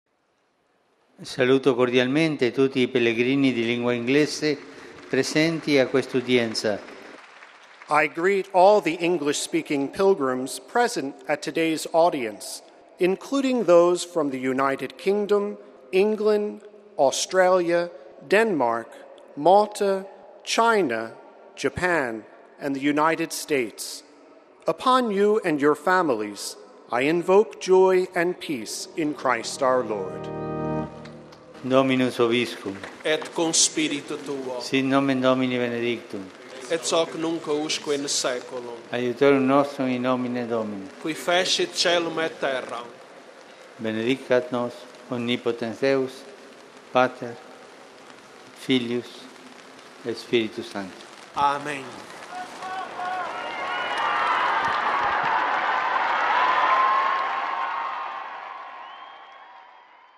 Pope Francis held his weekly general audience on Wednesday in Rome’s St. Peter’s Square.
Pope Francis then greeted the English-speaking pilgrims in Italian, which was translated into English by the aide: